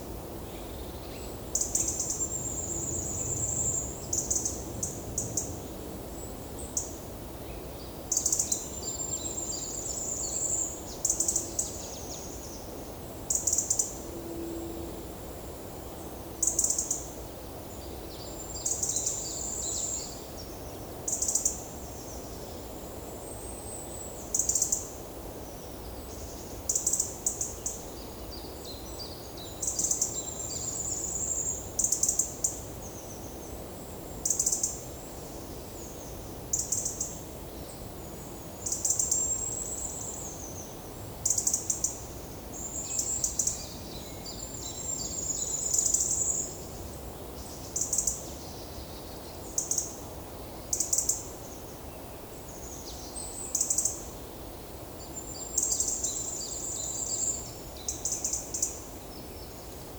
PEPR FORESTT - Monitor PAM - Renecofor
Erithacus rubecula
Regulus ignicapilla